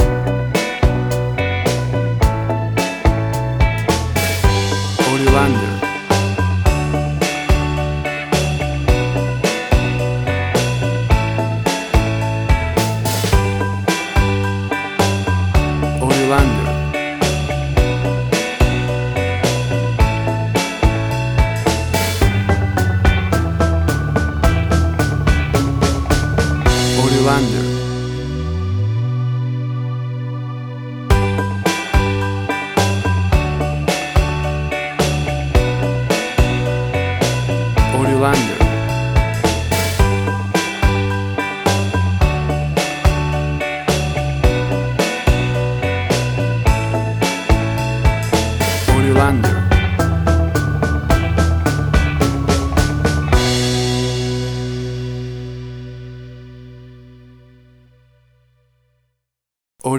WAV Sample Rate: 16-Bit stereo, 44.1 kHz
Tempo (BPM): 108